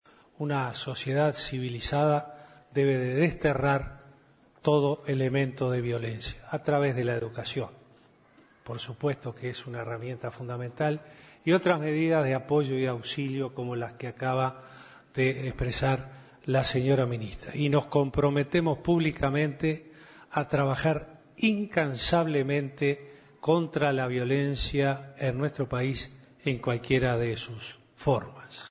Se realizo el tercer Consejo de Ministros público en Dolores, Soriano.